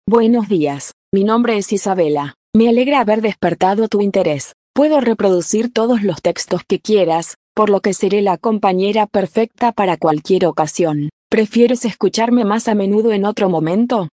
Professionelle Sprachausgabe zum Vorlesen und Vertonen beliebiger Texte
Die Stimmen klingen so natürlich, dass sie von menschlichen Sprechern kaum noch zu unterscheiden sind.
• Die Text-to-Speech Software bietet Ihnen alles, was Sie für die professionelle Vertonung benötigen